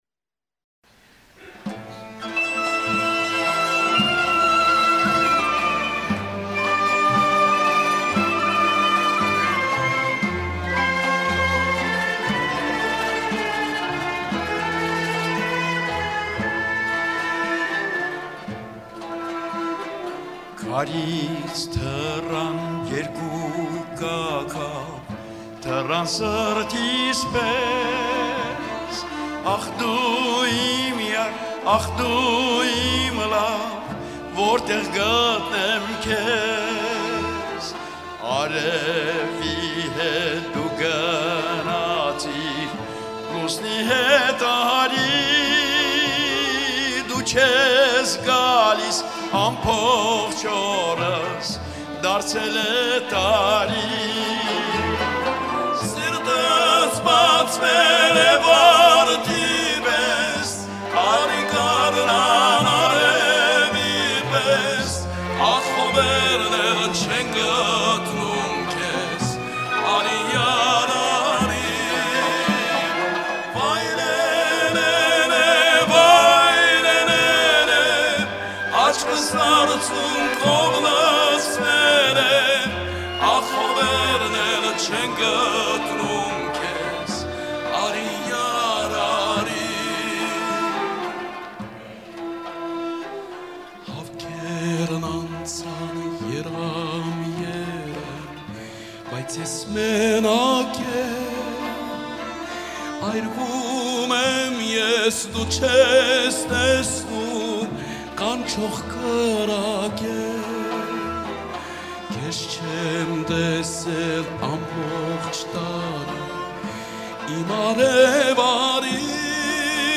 Wortegh kudnem kez  Armeens lied uit programma van het 4 Mei-Projekt 1992.